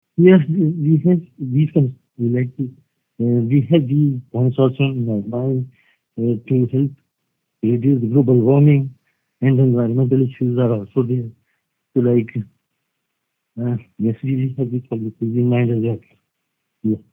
He was available by phone for this interview.